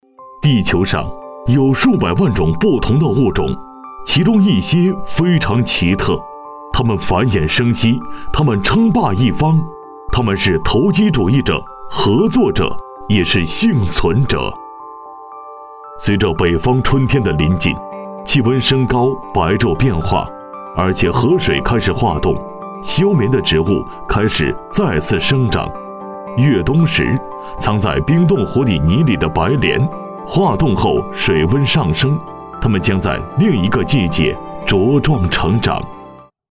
男26 地球生物（地理类）.mp3